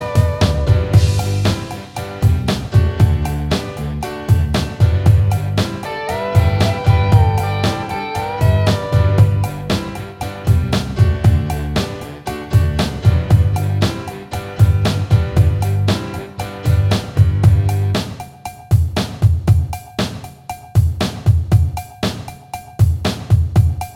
Minus Lead Guitar Rock 3:03 Buy £1.50